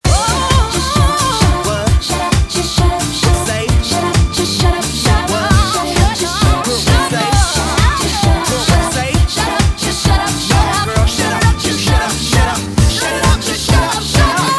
• Dance Ringtones